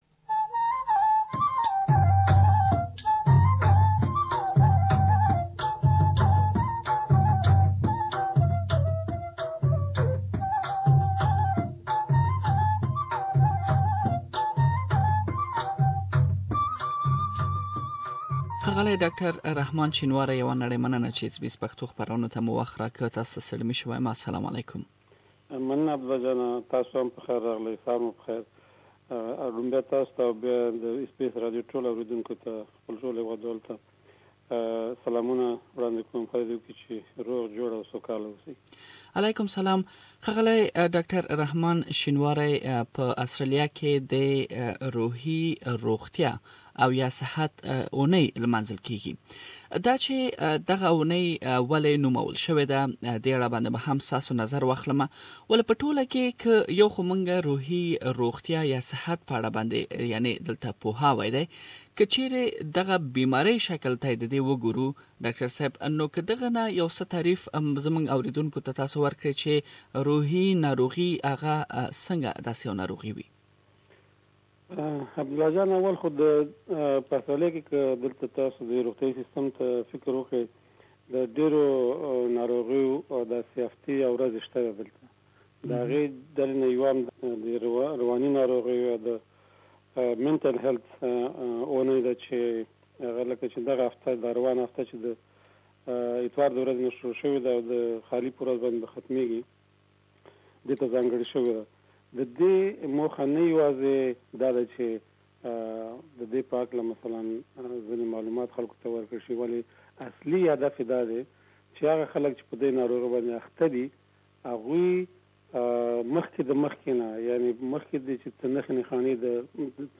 مرکه کړې چې تاسې د ښاغلي د مرکې لومړۍ برخه دلته اوريدلی شئ.